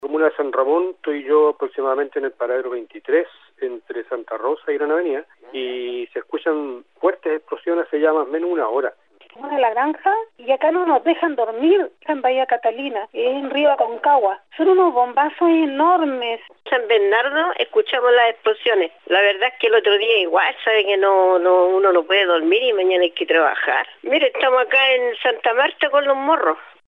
Incidentes en velorio
En redes sociales se viralizó un registro de lo denunciado por nuestros auditores, donde se evidencia un alto poder de fuego y gran cantidad de disparos.